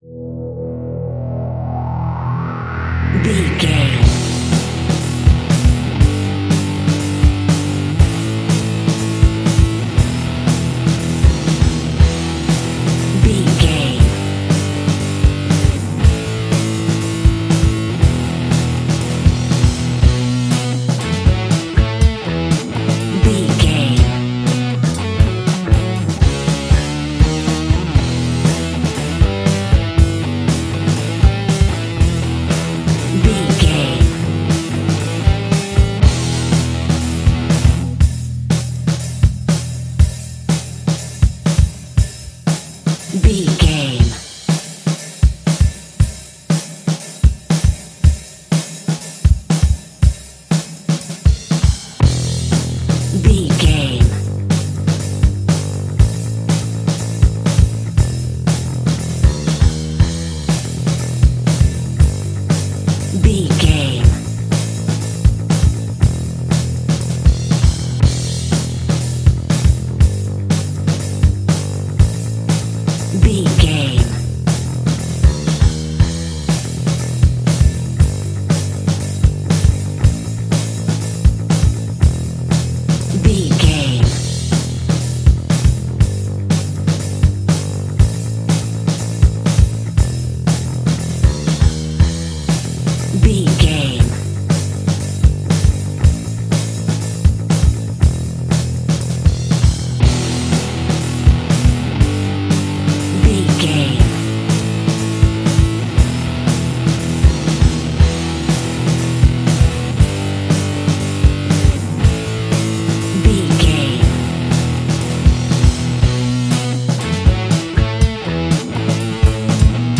Aeolian/Minor
groovy
electric guitar
bass guitar
drums
organ